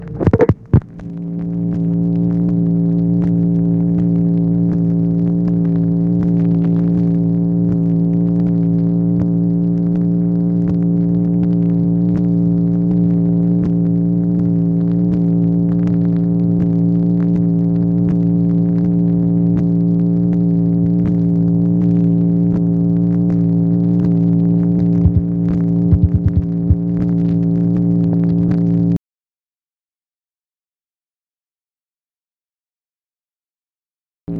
MACHINE NOISE, July 1, 1964
Secret White House Tapes | Lyndon B. Johnson Presidency